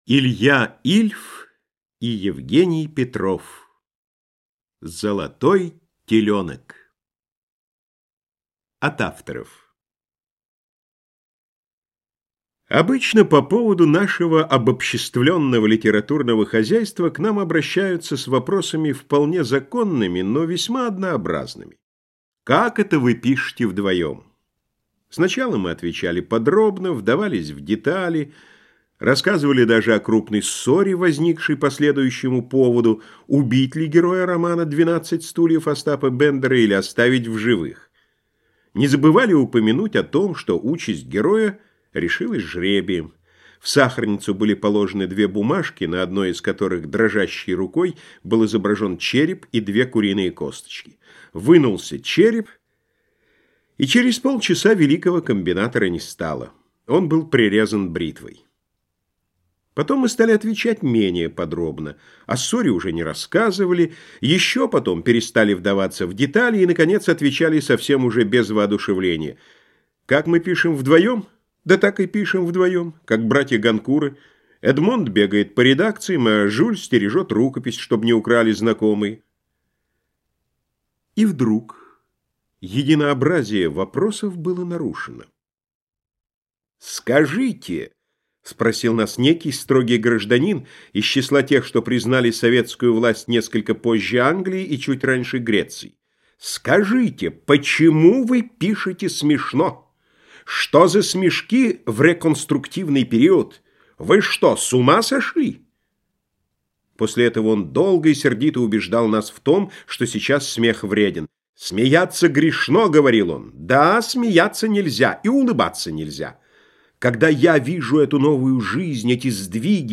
Аудиокнига Золотой теленок | Библиотека аудиокниг